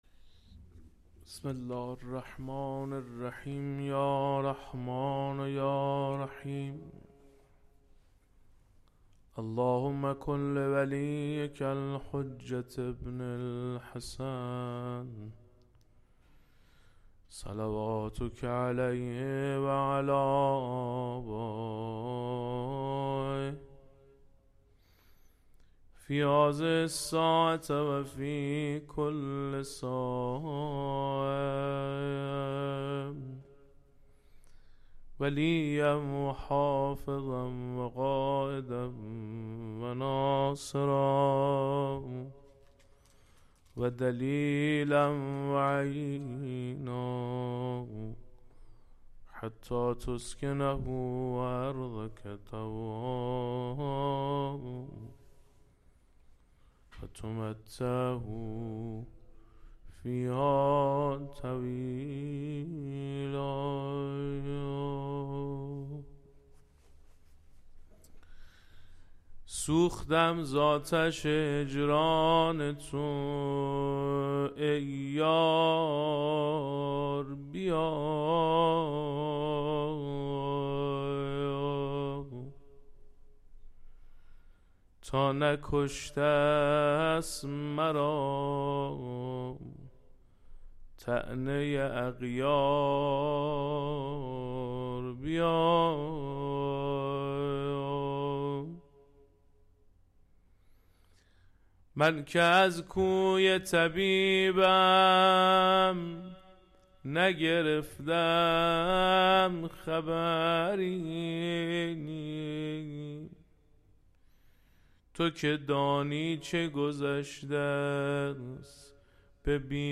مَدرَس مهديه باقرالعلوم - روضه حضرت زینب کبری (س) ۱۹ شهر رجب ۱۴۴۴
دسته بندی : مجالس روضه و مدح آل الله الاطهار علیهم صلوات الله الملک الجبار